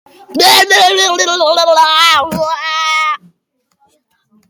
Sound Effects
Screaming Lol Funny